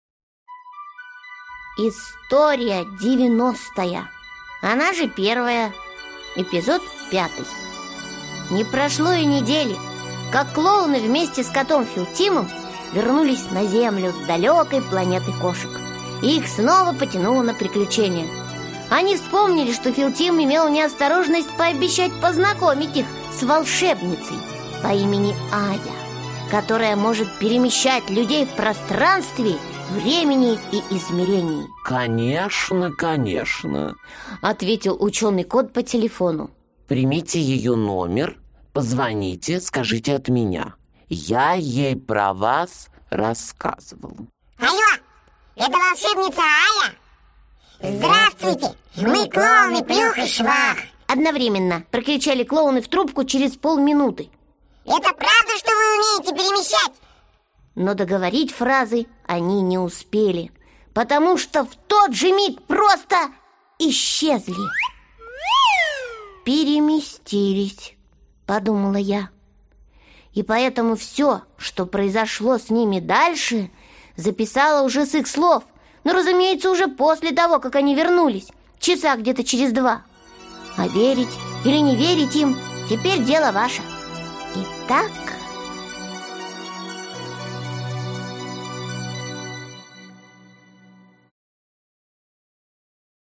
Аудиокнига Вселенский разум | Библиотека аудиокниг